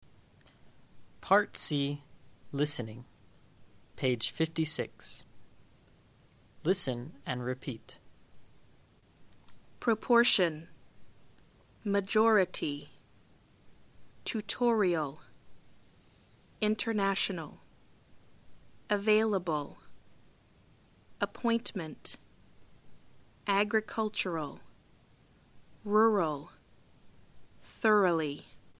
Listen and repeat.